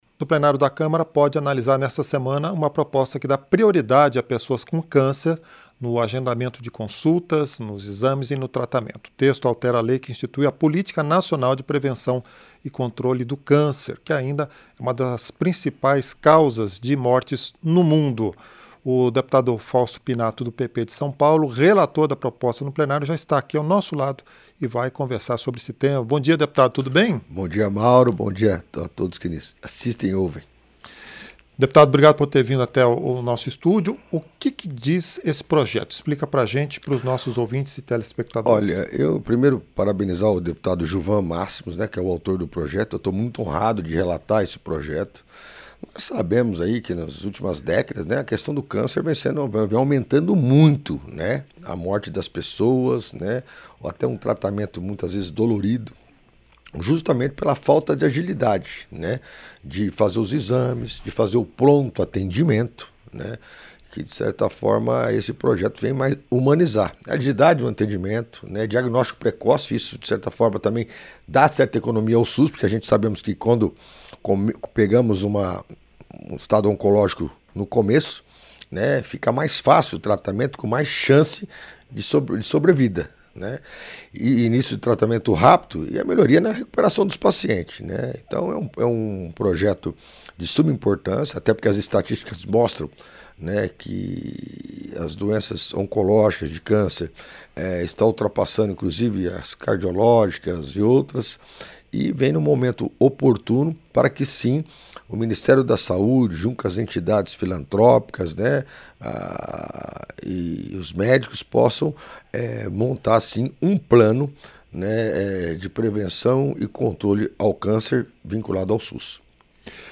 Entrevista - Dep. Fausto Pinato (PP-SP)